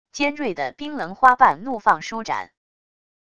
尖锐的冰棱花瓣怒放舒展wav音频